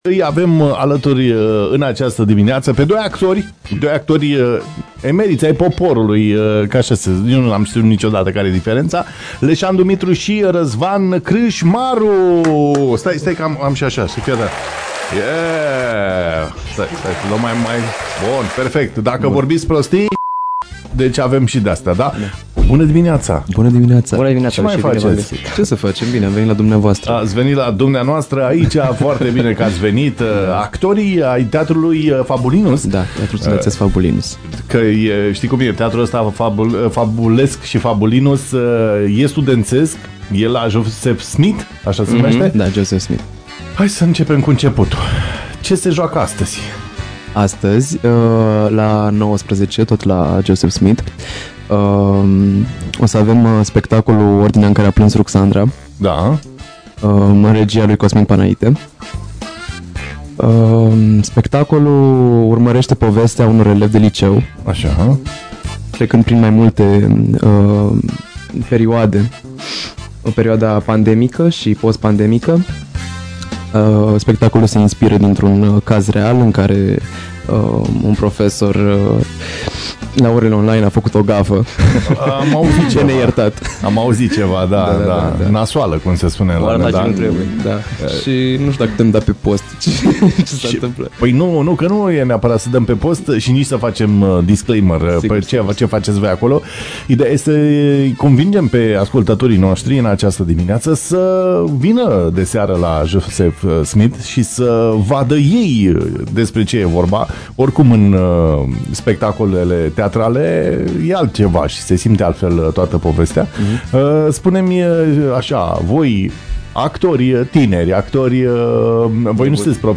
Gen: Blues.